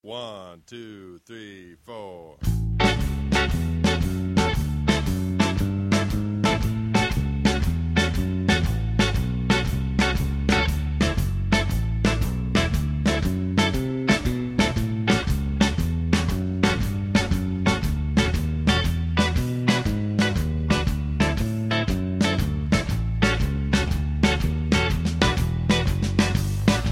Genre blues rock
• Instrumentation : Guitare